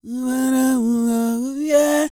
E-CROON 3018.wav